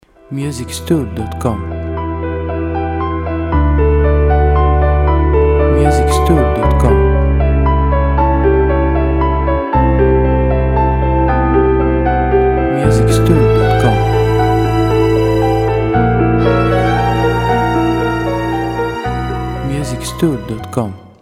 • Type : Instrumental
• Bpm : Adagio
• Genre : Ambient / Cinematic